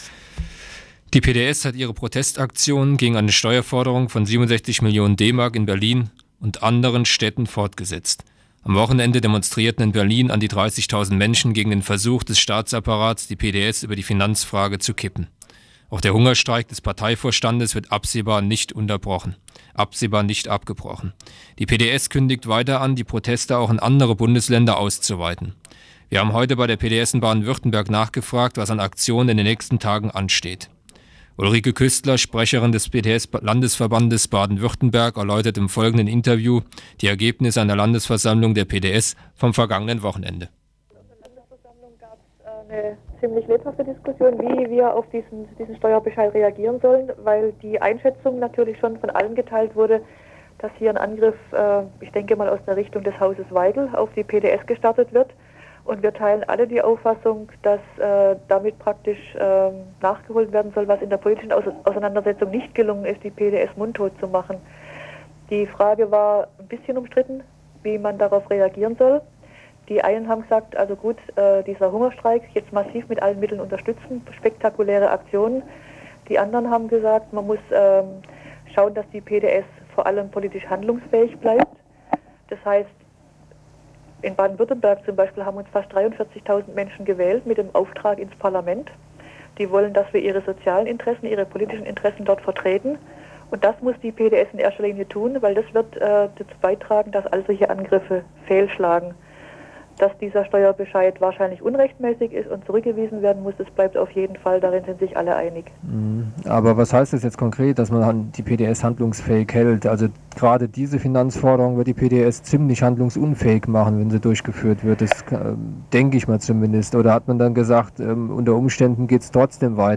Di 06.12.94 1 PDS-Ba-Wü. plant Aktionen gegen Steuerbescheid des Finanzamts. Interview